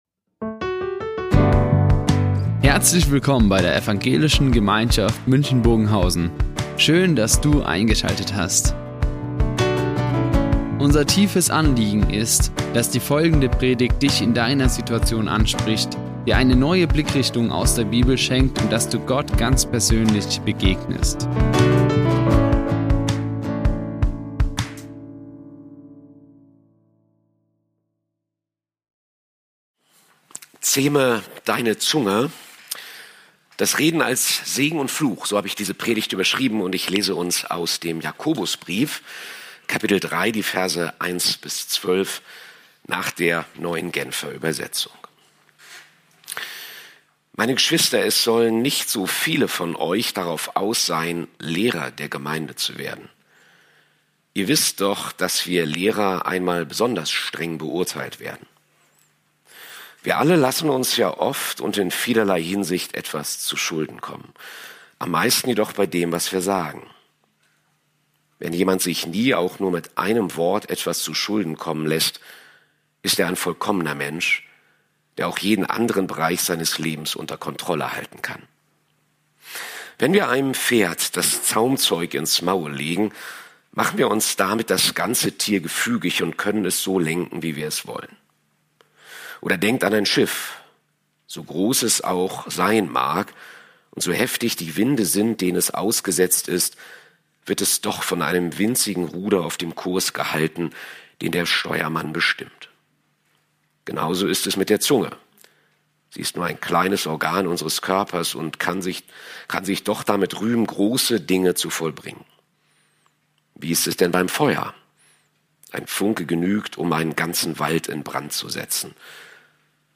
Das Reden als Segen und Fluch | Predigt Jakobus 3,1-12 ~ Ev.